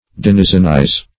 Search Result for " denizenize" : The Collaborative International Dictionary of English v.0.48: Denizenize \Den"i*zen*ize\, v. t. To constitute (one) a denizen; to denizen.